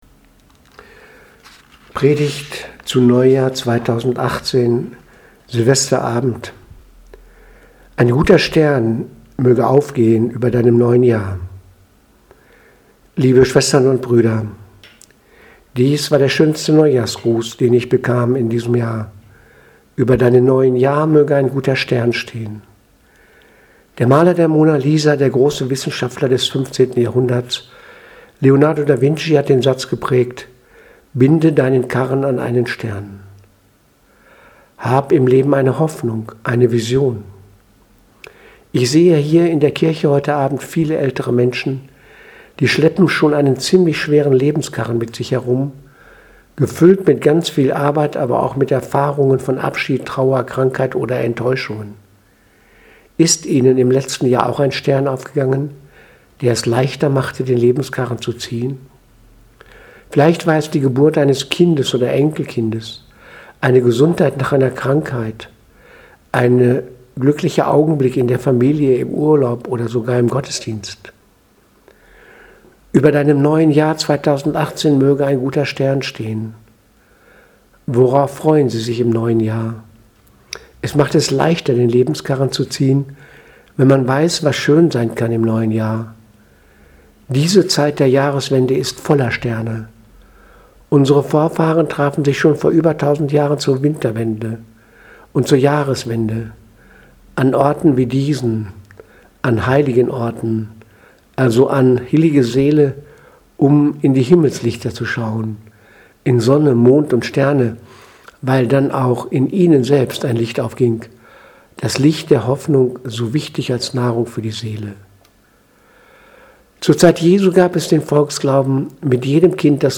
Predigt vom 1.1.2018 – Neujahr